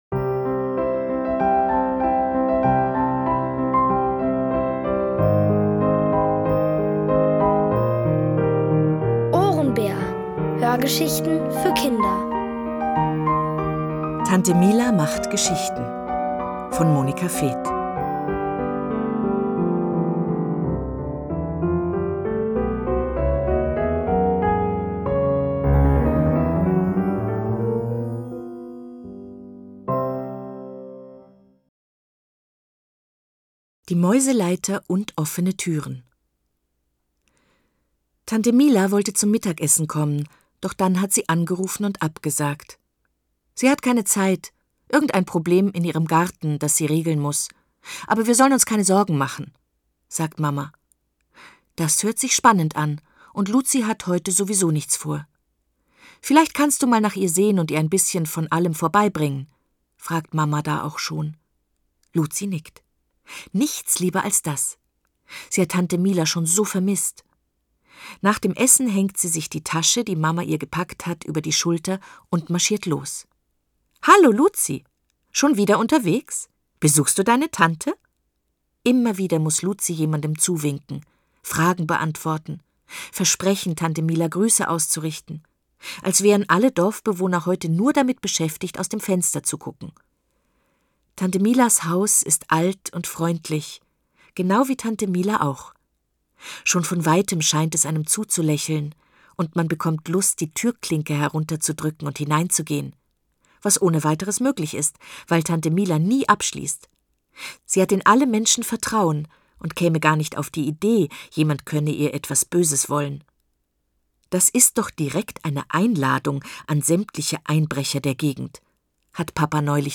Von Autoren extra für die Reihe geschrieben und von bekannten Schauspielern gelesen.
Es liest: Eva Mattes.